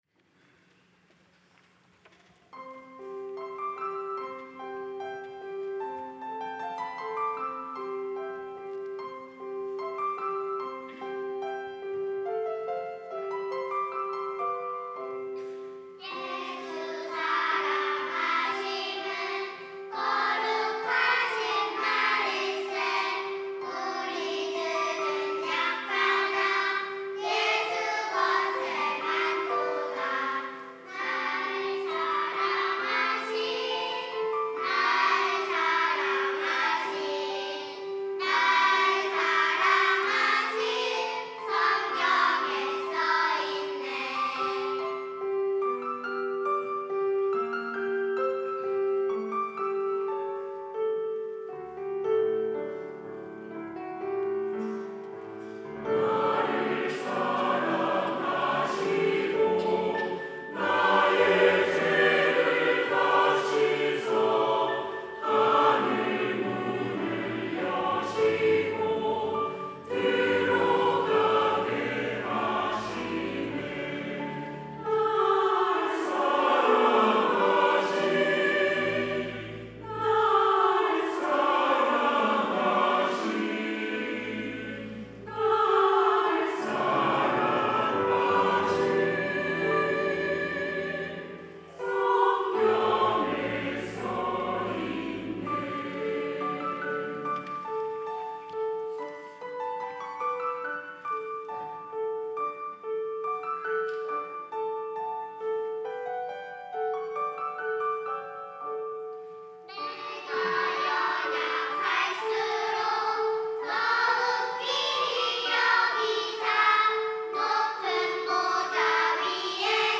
유년부, 시온 찬양대